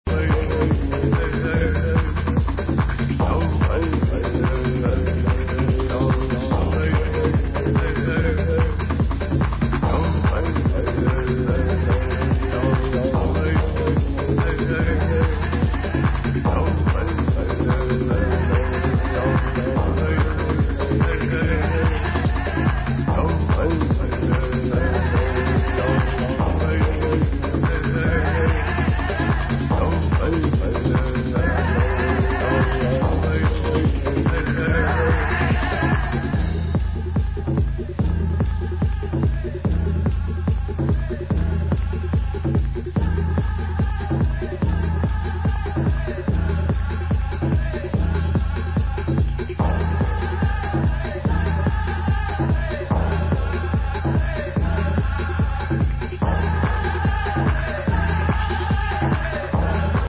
Этно-Трансовая музыка
Трансовая музыка.